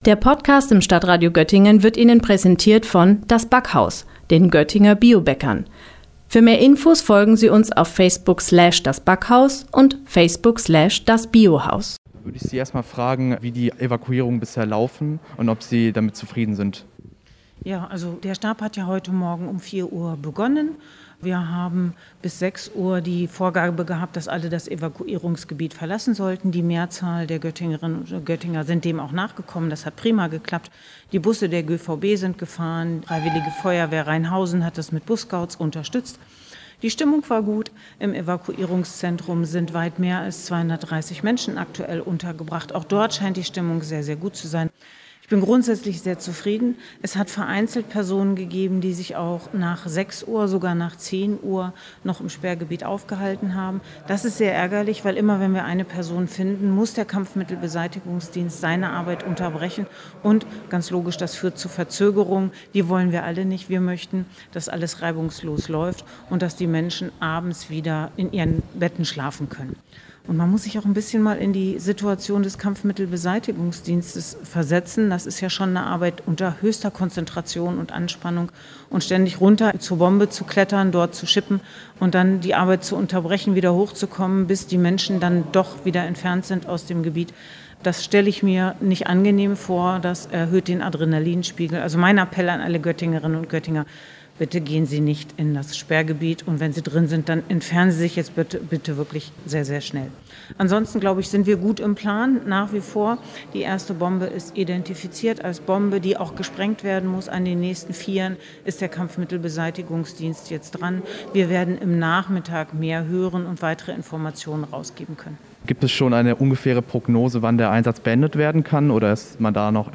Beiträge > Oberbürgermeisterin Broistedt über die Bombenräumung in der Göttinger Weststadt - StadtRadio Göttingen